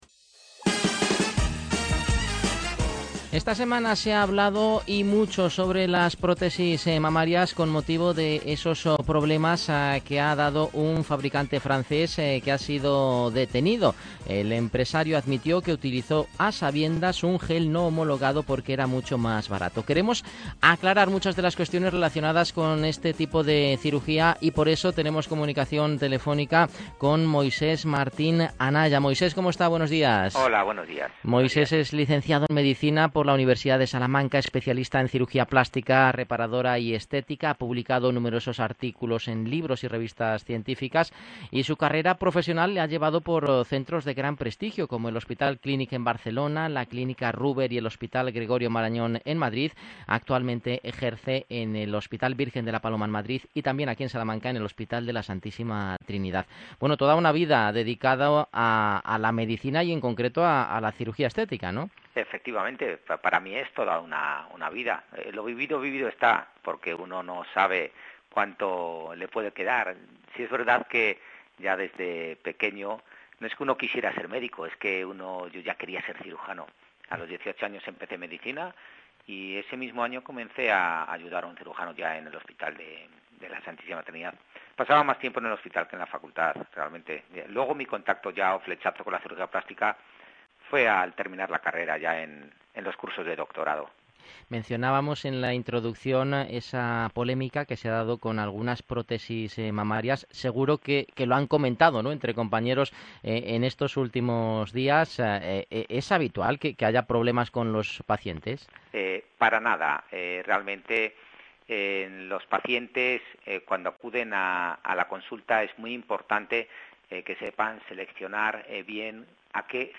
admin Os dejo una entrevista que me han hecho recientemente en una radio nacional http